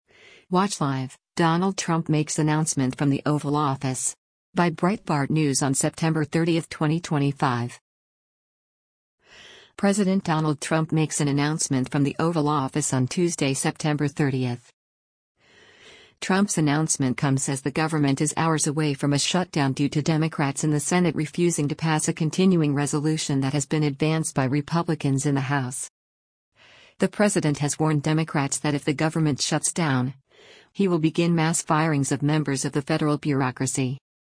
President Donald Trump makes an announcement from the Oval Office on Tuesday, September 30.